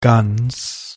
Ääntäminen
Synonyymit goose iron Ääntäminen UK US Tuntematon aksentti: IPA : /ɡuːs/ Haettu sana löytyi näillä lähdekielillä: englanti Käännös Ääninäyte Substantiivit 1.